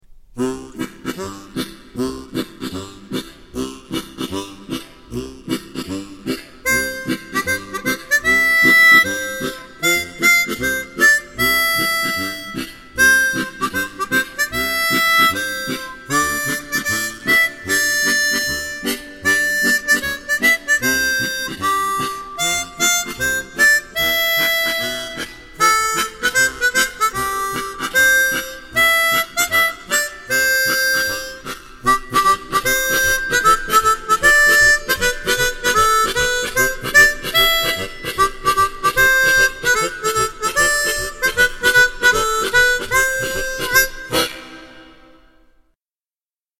ベース付 フルスケールコードハーモニカ
コードにはシングルリードを、ベースにはオクターブ違いの重厚なダブルリードを採用しています。